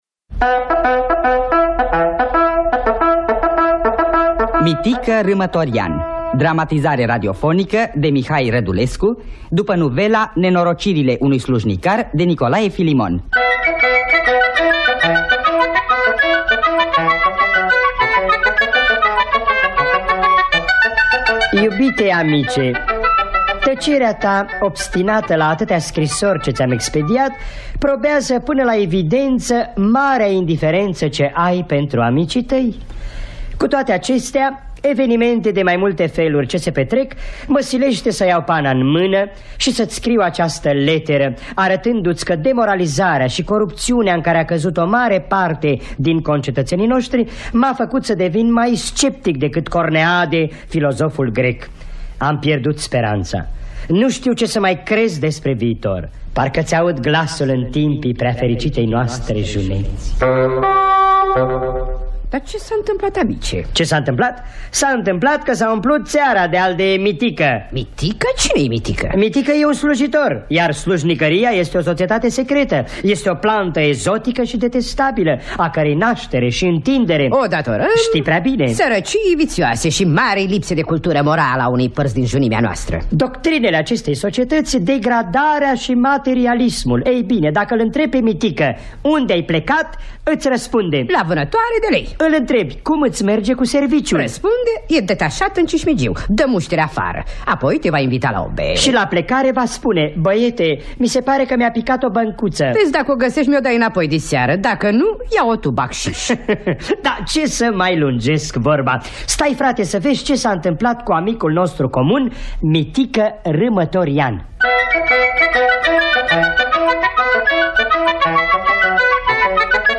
Mitică Râmătorian” de Nicolae Filimon – Teatru Radiofonic Online